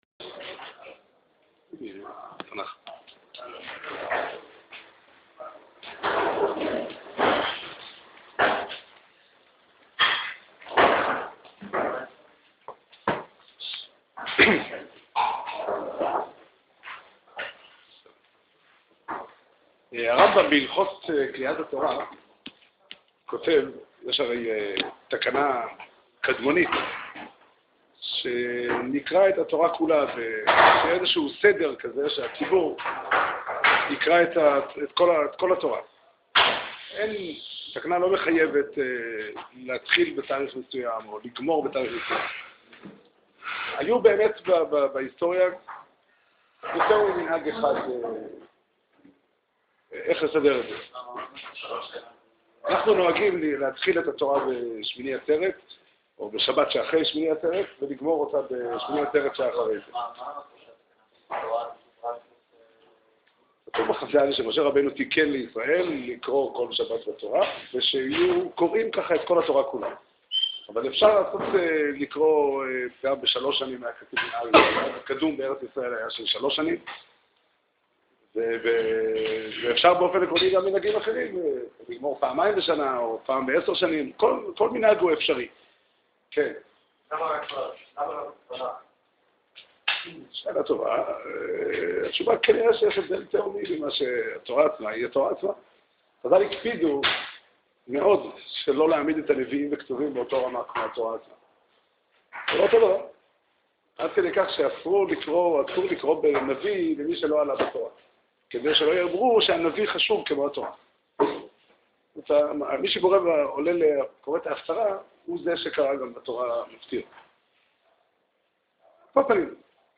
שיעור שנמסר בבית המדרש פתחי עולם בתאריך י"ט תמוז תשפ"ד